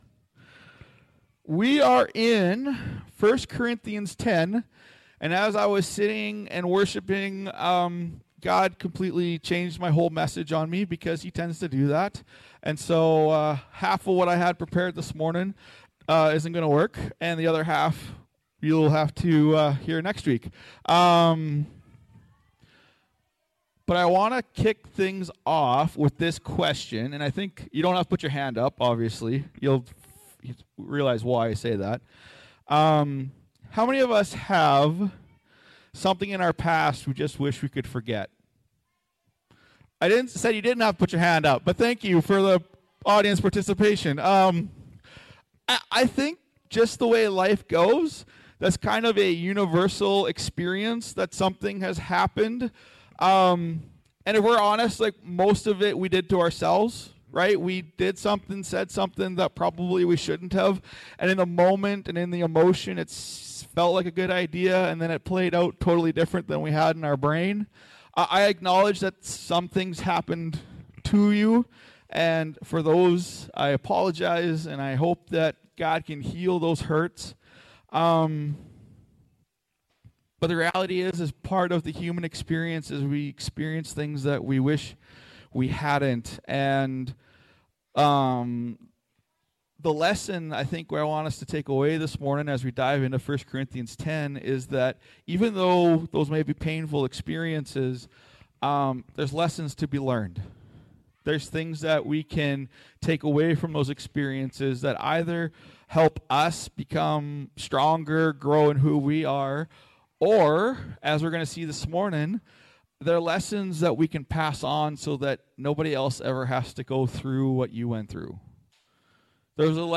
Sermons | OneChurch